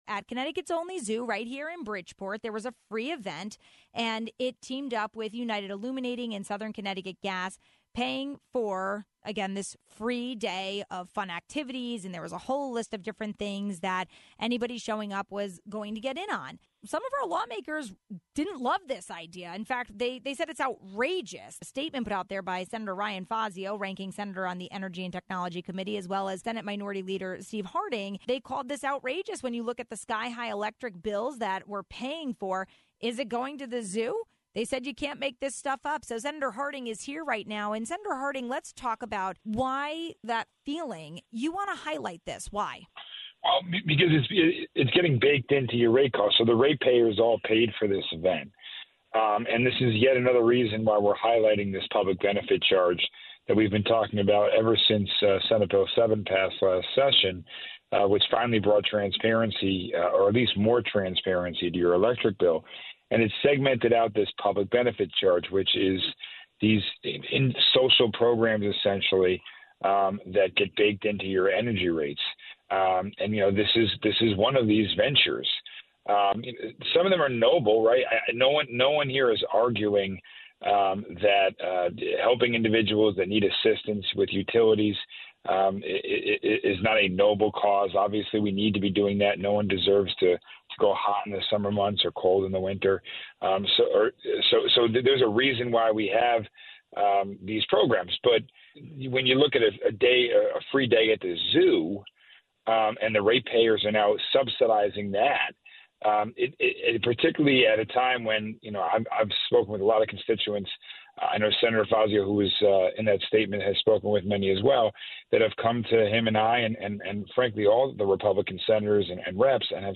We spoke to Senator Steve Harding about the public benefits section of utility bills and its connection to this event.